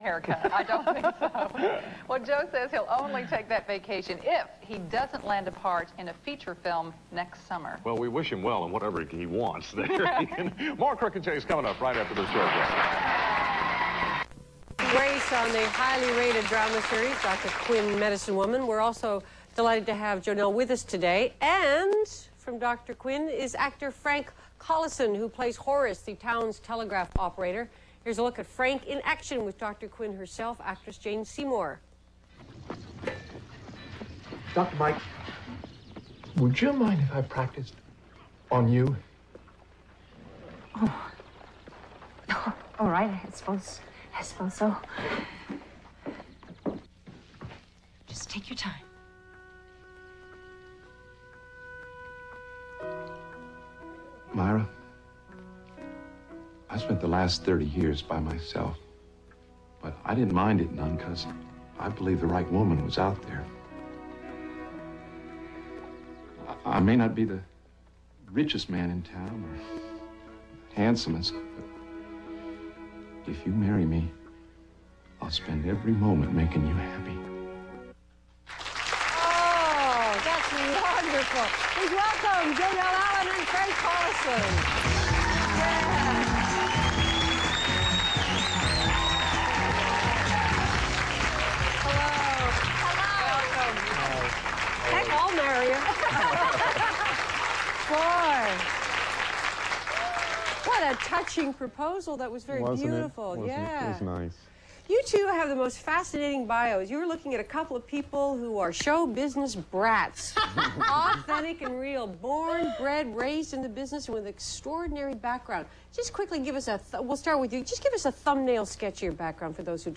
A rare interview with Frank Collison
and Jonelle Allen.
frankandjonelleinterview_arch001.rm